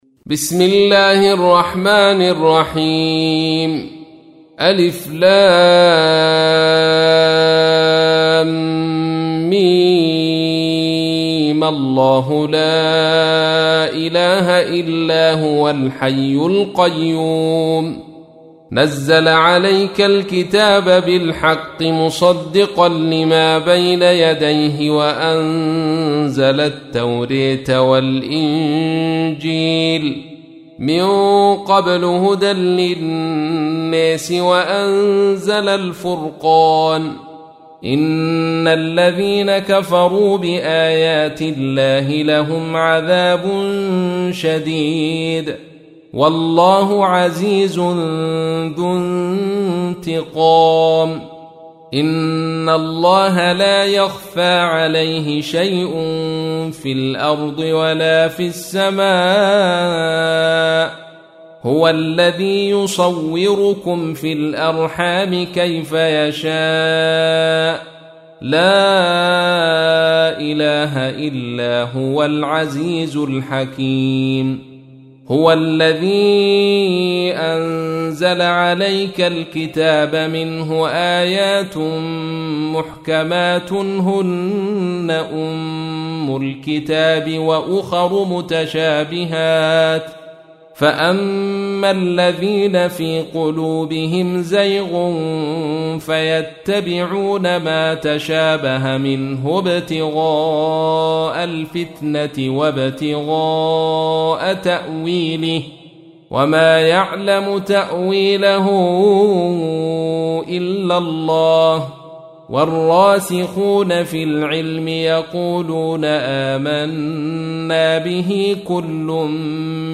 تحميل : 3. سورة آل عمران / القارئ عبد الرشيد صوفي / القرآن الكريم / موقع يا حسين